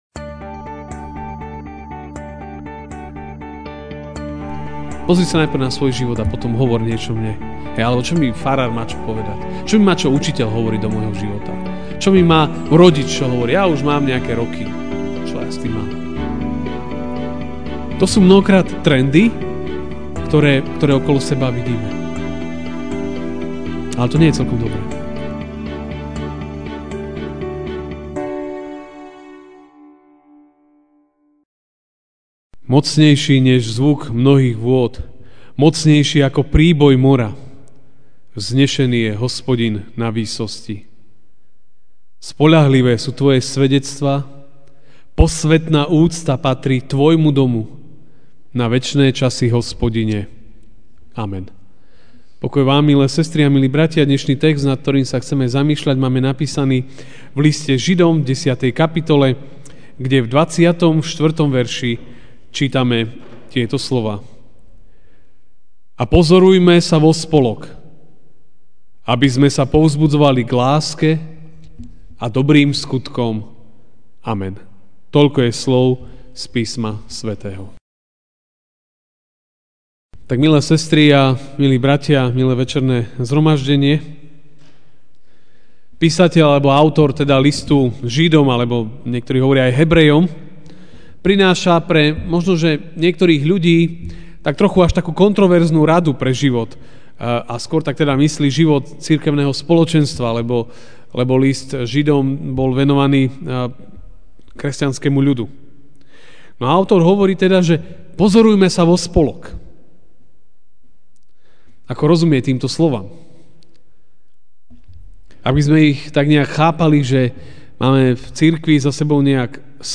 jan 29, 2017 Pozorujme sa vospolok MP3 SUBSCRIBE on iTunes(Podcast) Notes Sermons in this Series Večerná kázeň: Pozorujme sa vospolok (Žid. 10, 24) A pozorujme sa vospolok, aby sme sa povzbudzovali k láske a dobrým skutkom.